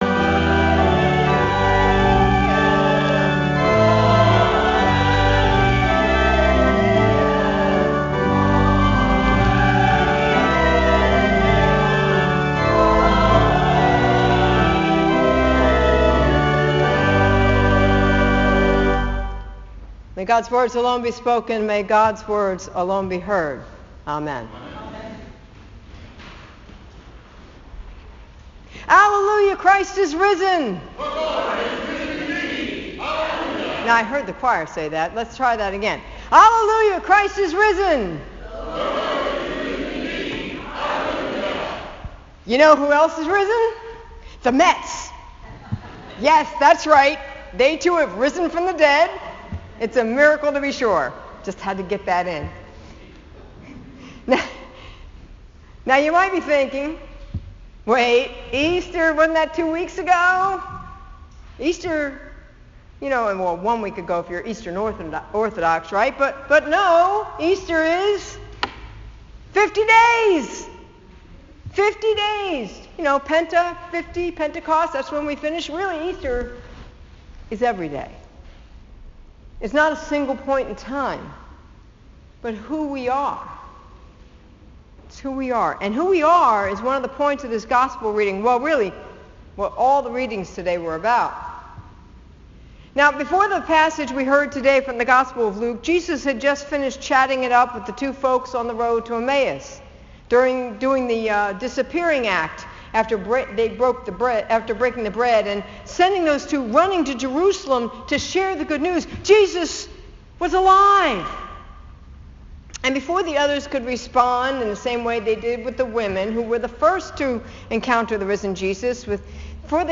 I want to share with you a story that came to mind on Friday as I was mulling the texts for today to write a sermon.